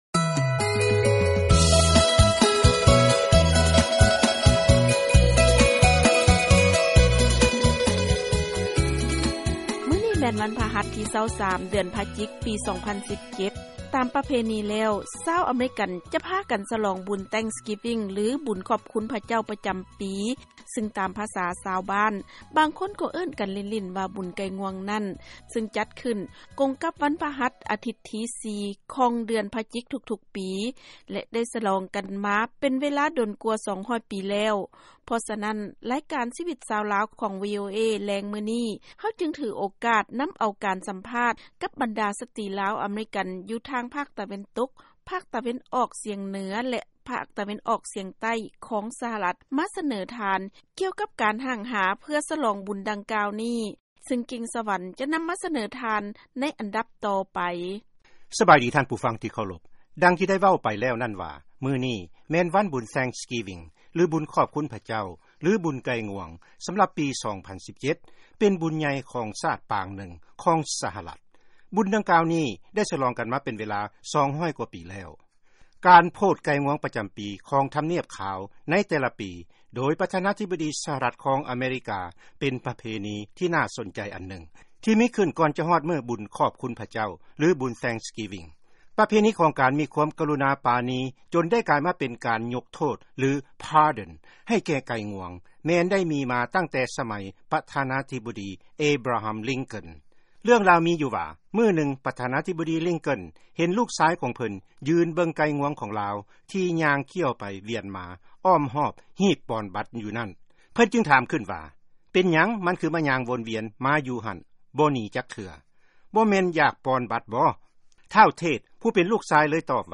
ເຊີນຟັງການສຳພາດ ສະຫລອງບຸນວັນຂອບຄຸນພະເຈົ້າ